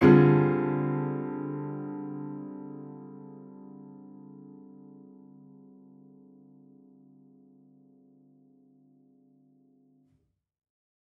Index of /musicradar/gangster-sting-samples/Chord Hits/Piano
GS_PiChrd-Dmin7+9.wav